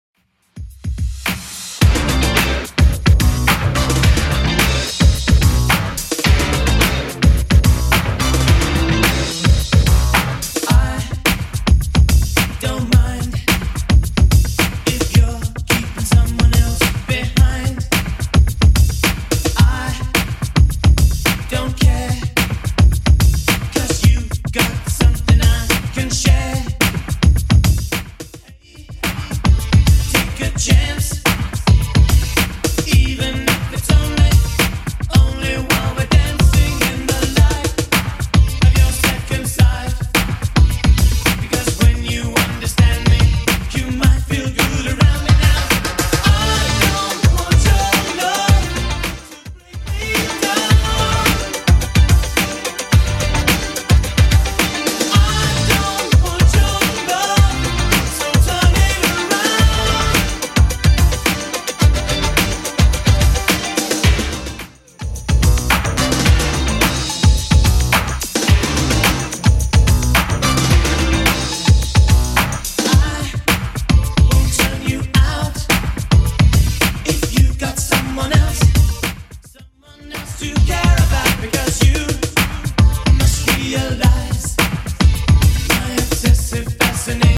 Genre: 70's Version: Clean BPM: 108